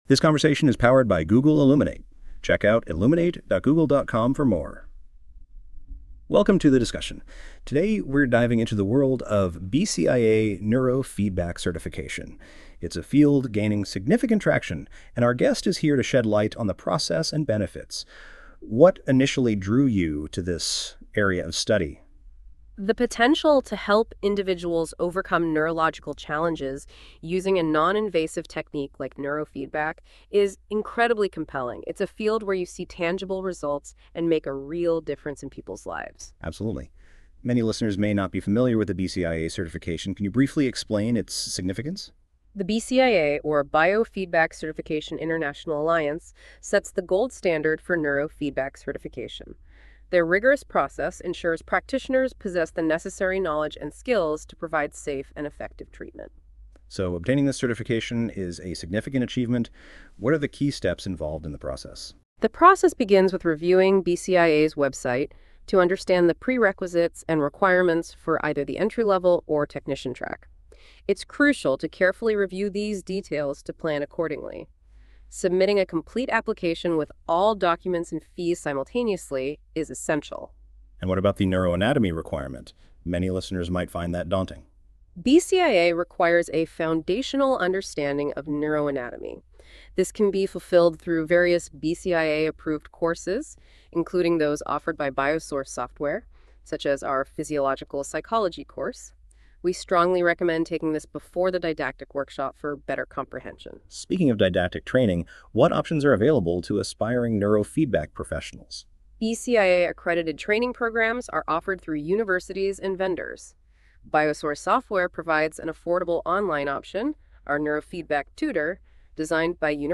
Click on the podcast icon for a Google Illuminate discussion.